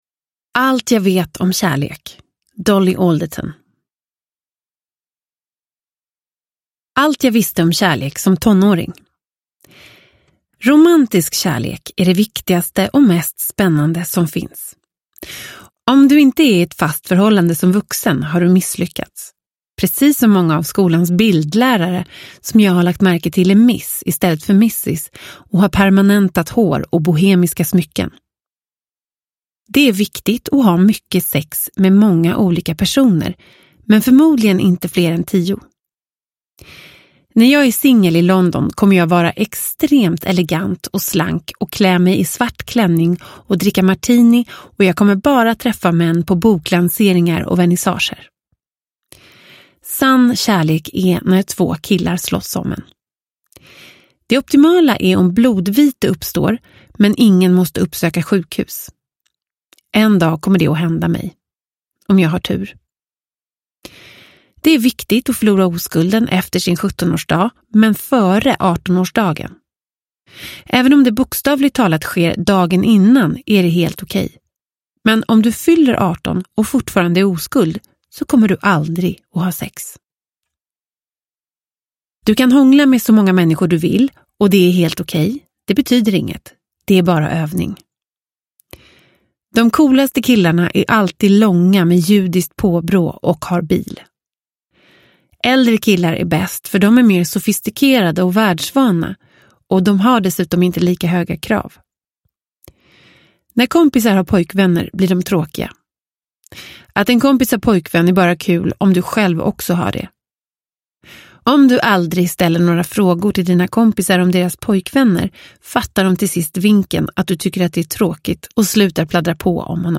Allt jag vet om kärlek – Ljudbok – Laddas ner
Uppläsare: Emma Molin